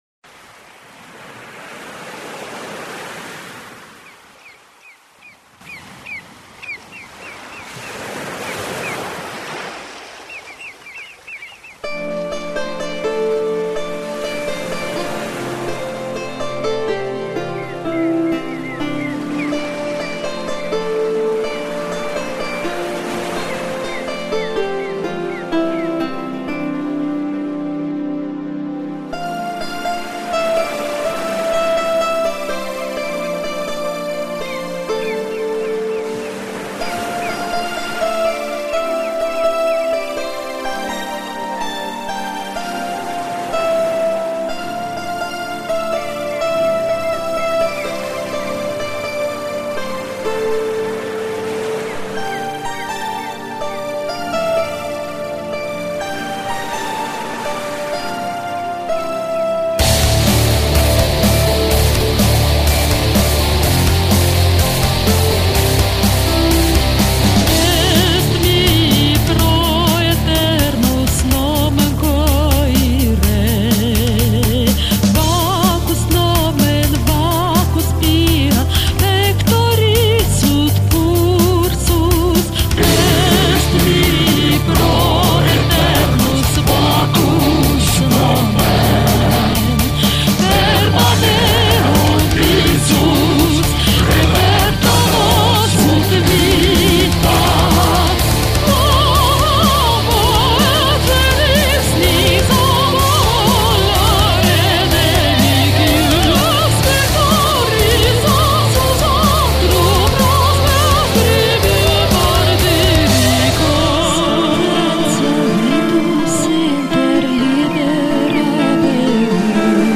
Covers album Tape